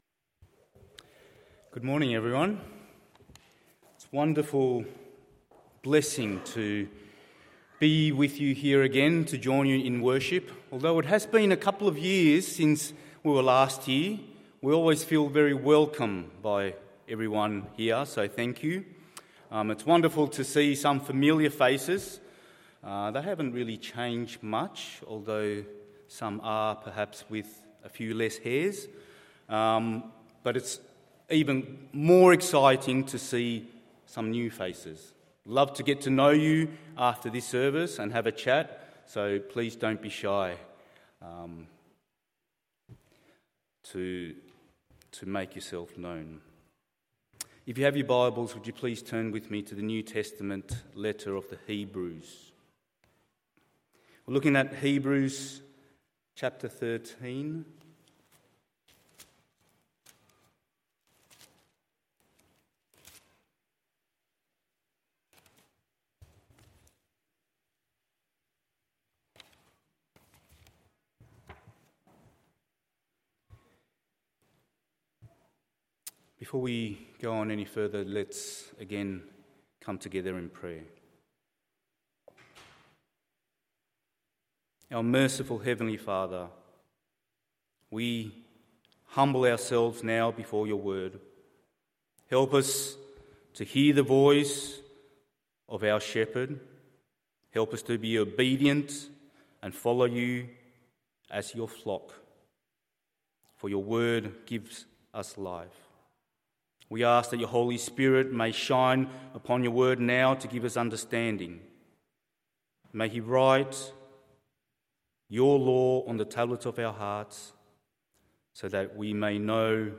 Morning Service Hebrews 13:11-14…